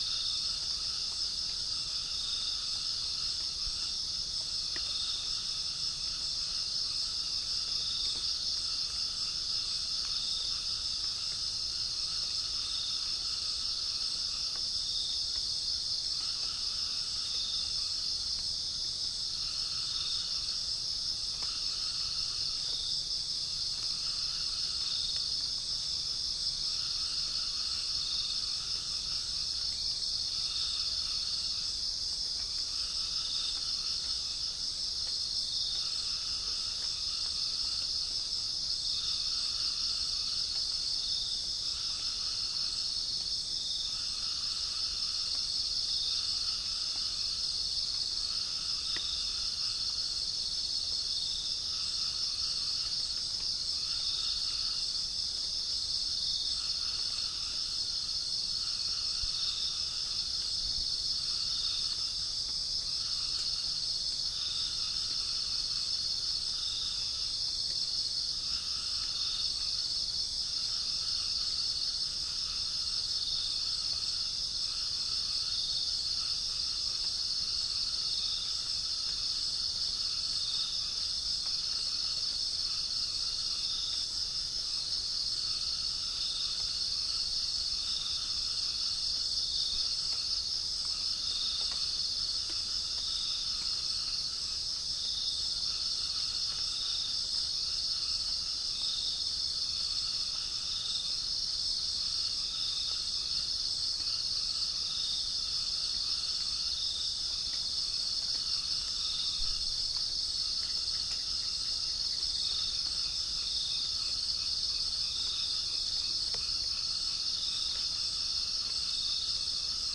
Chalcophaps indica
Gallus gallus
Spilopelia chinensis
Geopelia striata
Pycnonotus goiavier